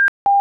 При работе от сети и в случае перегрева к сигналу бипера добавляется сигнал длительностью 0.15cек тоном 800 Гц.